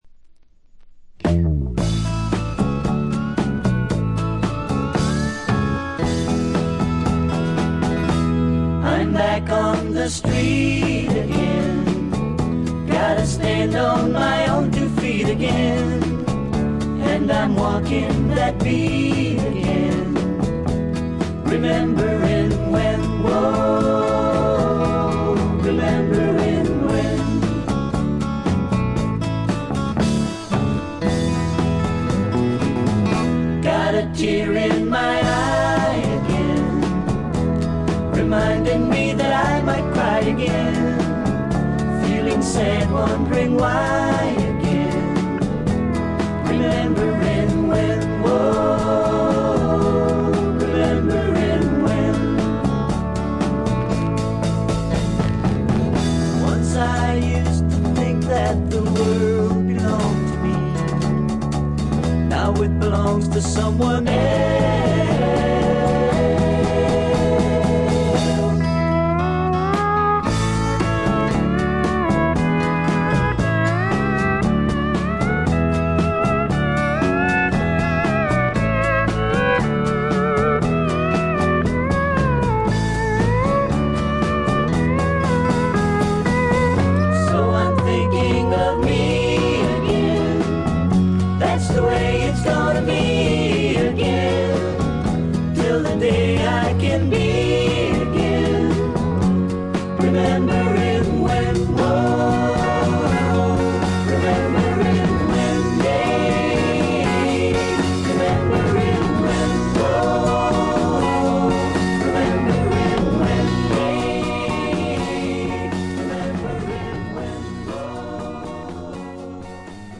ホーム > レコード：カントリーロック
これ以外は軽微なバックグラウンドノイズ少々、軽微なチリプチ少々。
試聴曲は現品からの取り込み音源です。